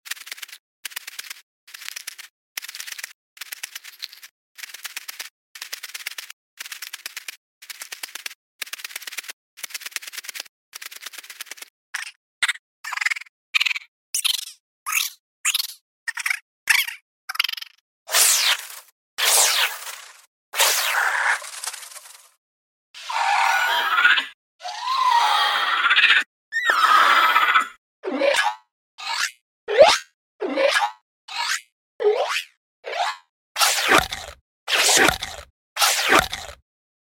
Cave And Blood Crawler Sounds!